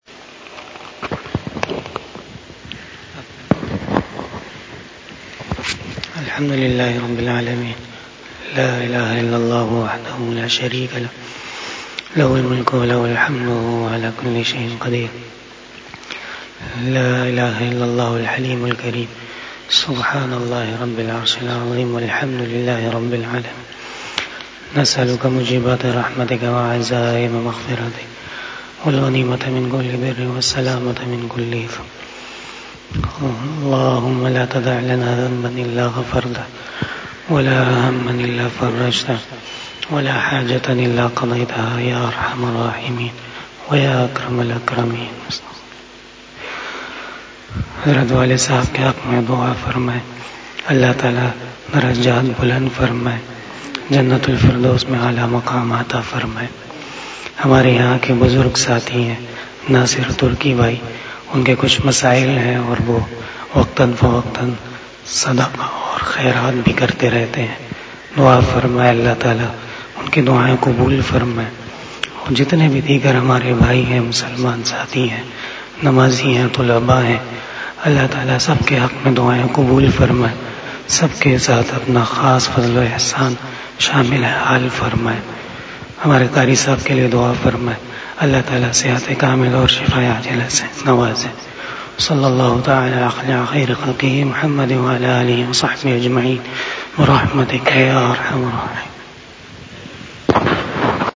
Dua 17 Jan 2021
Dua After Fajar Namaz
دعا بعد نماز فجر